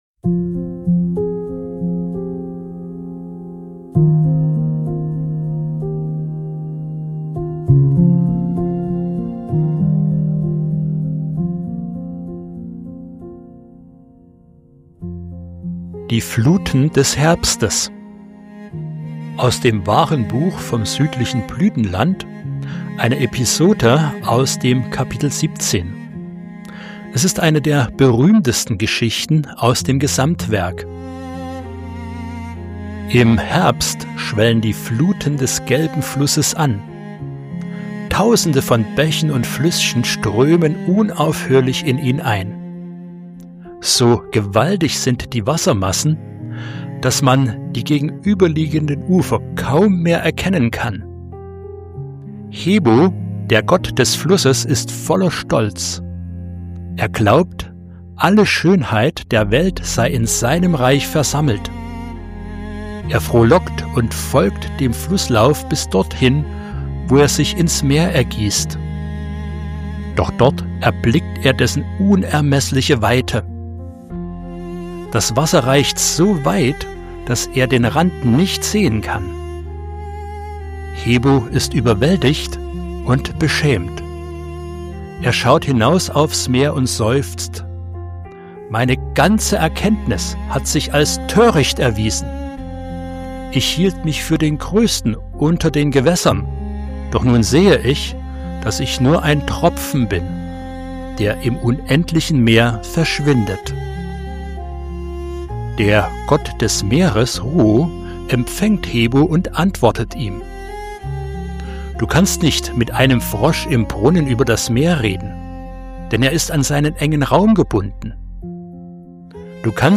Sprecher
Musik